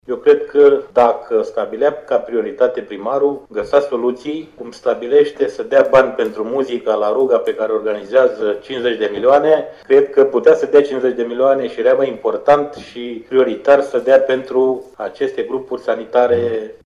Dacă primarul găsea soluţii nu mai stabilea să dea bani pentru muzica de la ruga pe care o organizează cu 50 de milioane, ci le dădea prioritar pentru grupurile sanitare”, a spus reprezentantul Guvernului în teritoriu.